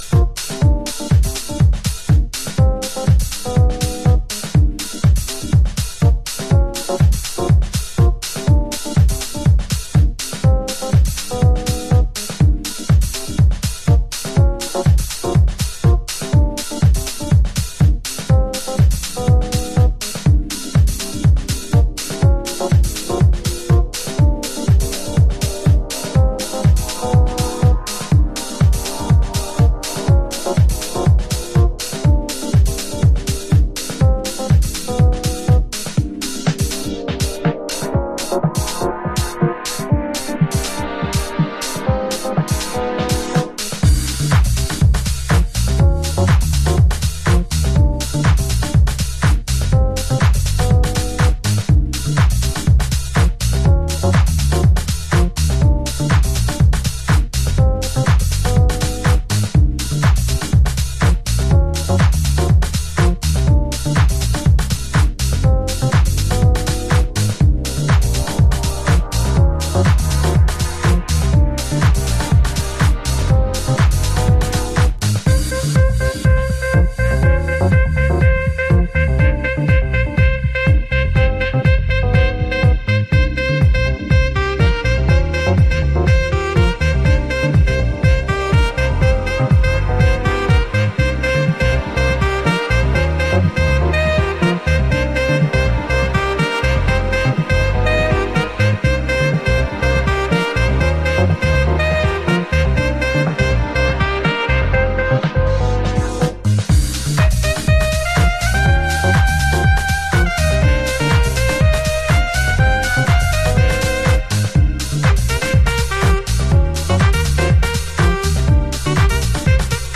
House / Techno
芳醇なアコースティックでグルーヴする